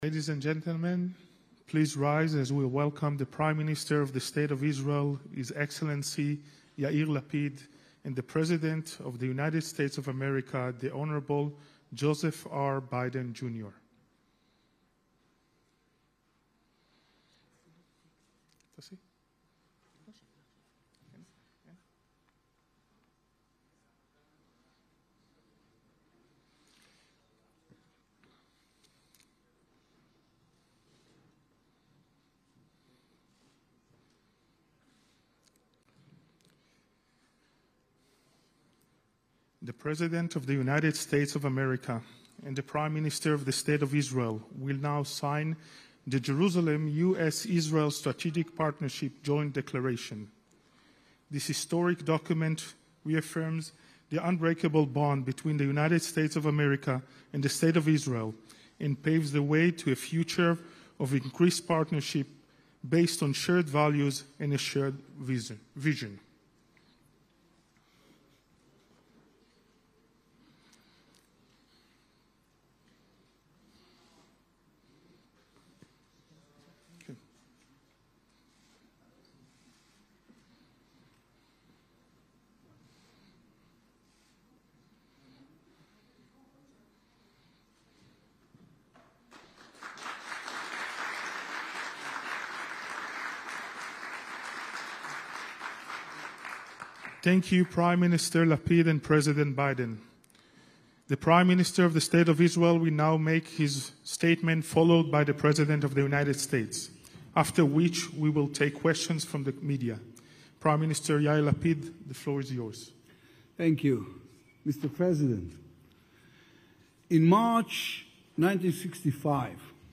Joe Biden and Yair Lapid - Press Conference in Jerusalem at the 'Jerusalem Declaration' Signing Ceremony (text-audio-video)
joebidenyairlapidjerusalempresser.mp3